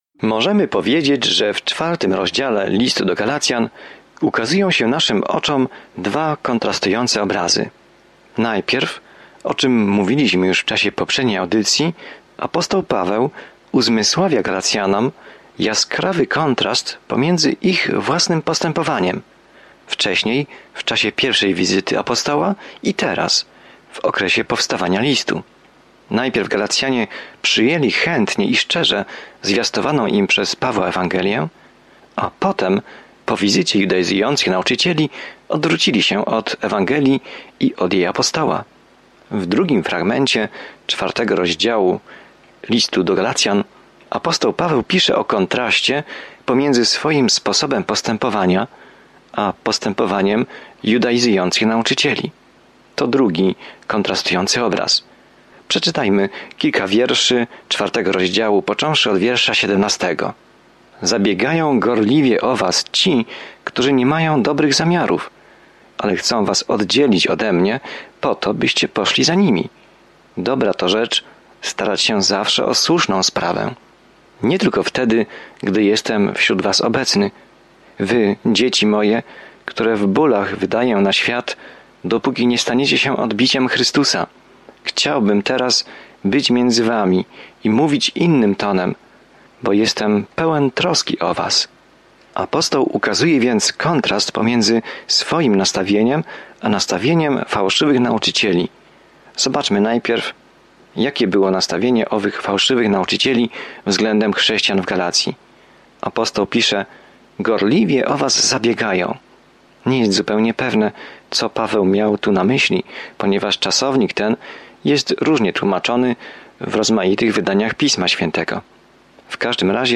Pismo Święte Galacjan 4:17-23 Dzień 12 Rozpocznij ten plan Dzień 14 O tym planie „Tylko przez wiarę” jesteśmy zbawieni, a nie przez cokolwiek, co czynimy, by zasłużyć na dar zbawienia – takie jest jasne i bezpośrednie przesłanie Listu do Galacjan. Codzienna podróż przez Galacjan, słuchanie studium audio i czytanie wybranych wersetów słowa Bożego.